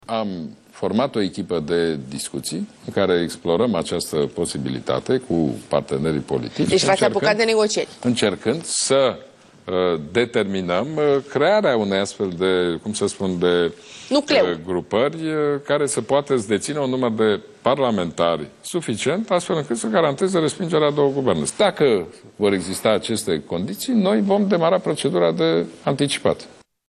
Ludovic Orban, aseară la Realitatea Plus: